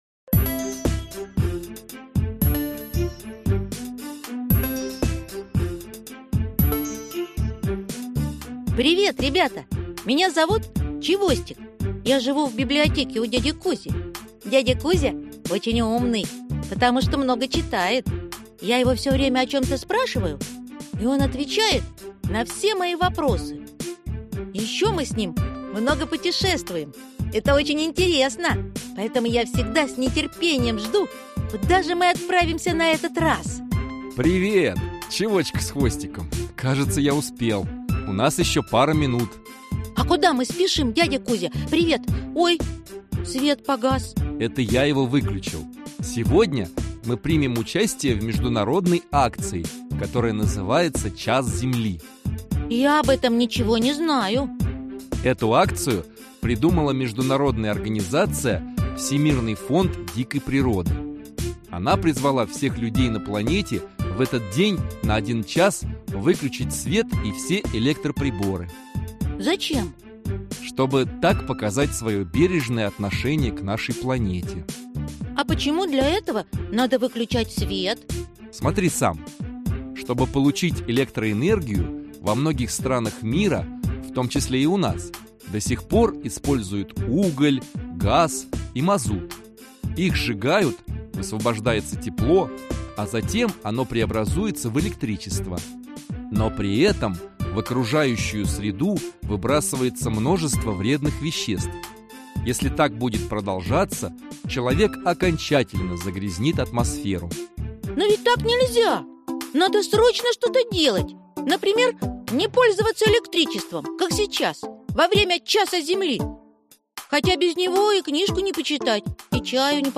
Аудиокнига Чистая электроэнергетика | Библиотека аудиокниг